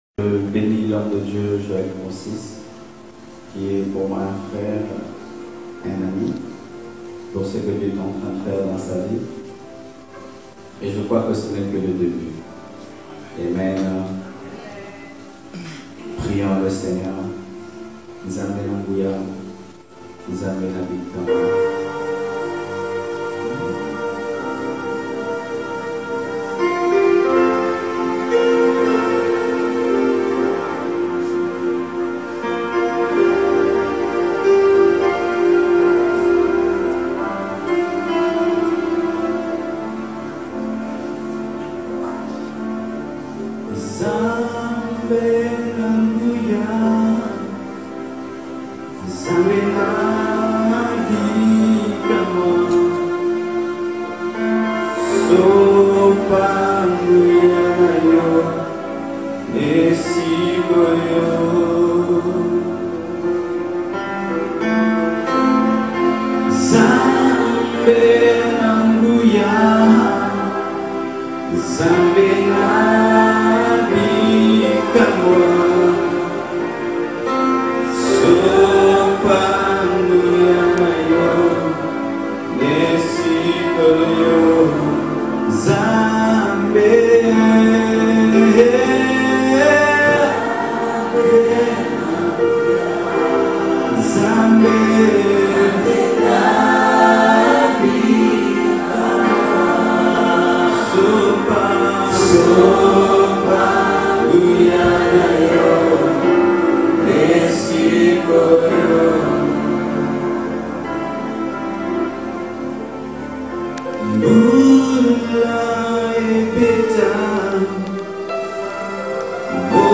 PRÉDICATIONS AUDIO | SCHOOL FOR CHRIST FONDATION